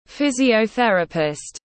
Physiotherapist /ˌfɪz.i.əʊˈθer.ə.pɪst/